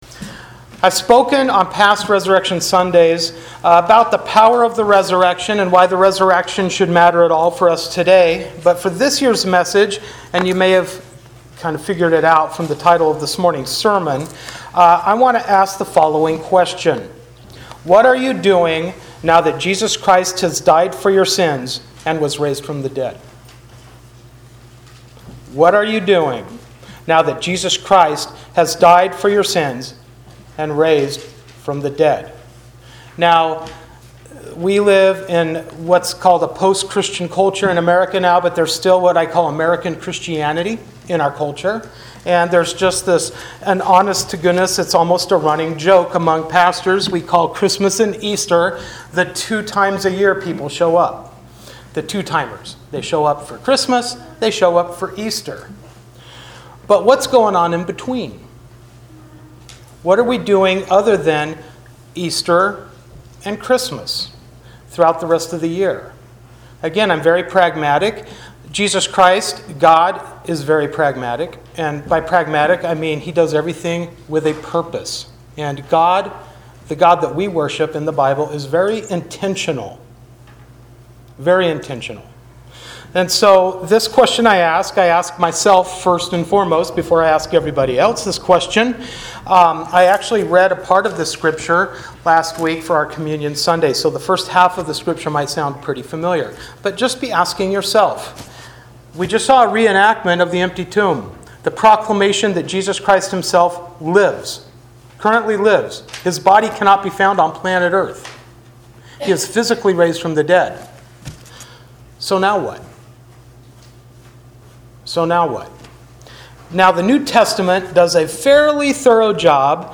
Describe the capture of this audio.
Hebrews 10:19-25 Service Type: Sunday Morning Worship Bible Text